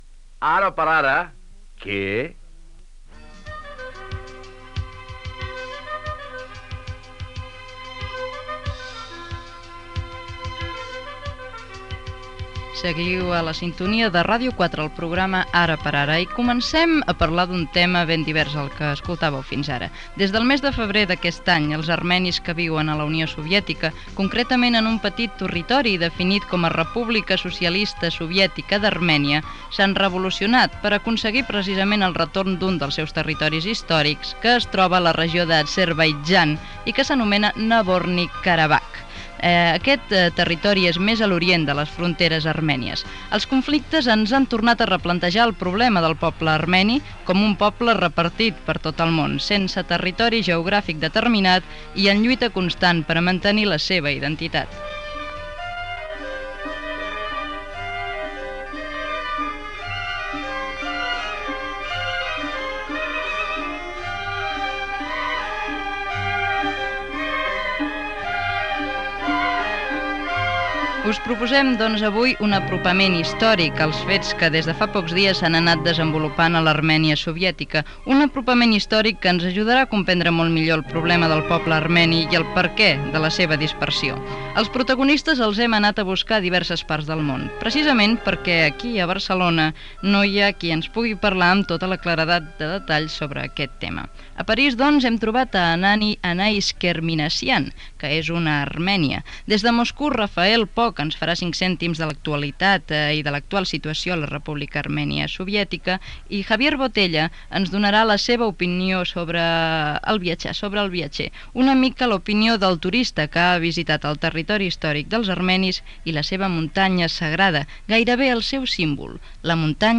Identificació del programa, reportatge sobre la República Soviètica d'Armènia Gènere radiofònic Info-entreteniment